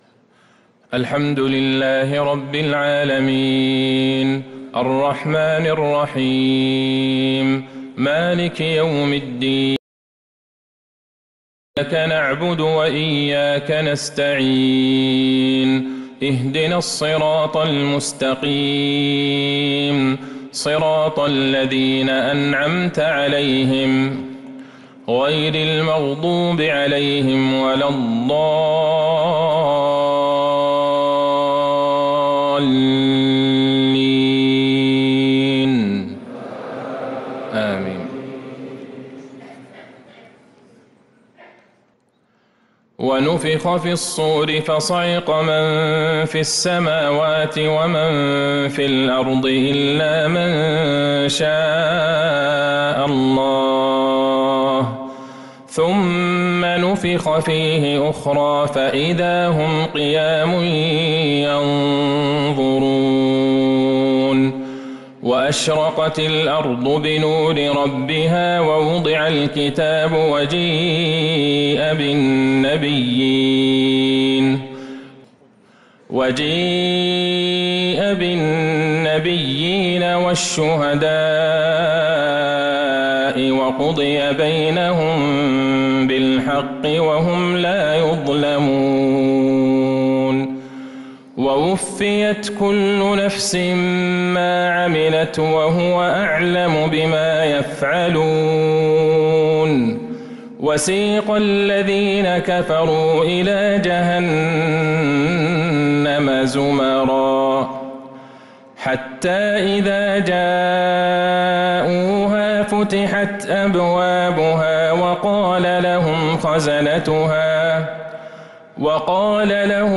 عشاء الجمعة 9 محرم 1447هـ | خواتيم سورة الزمر 68-75 | Isha prayer from Surat az-Zumar 4-7-2025 > 1447 🕌 > الفروض - تلاوات الحرمين